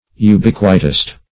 Ubiquitist \U*biq"ui*tist\ ([-u]*b[i^]k"w[i^]*t[i^]st), n.